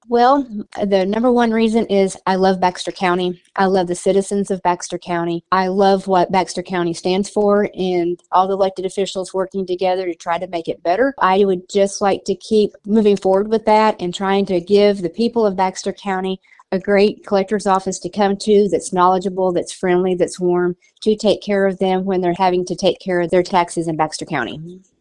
Smith spoke with KTLO News and was asked why she announced her re-election bid for Baxter County.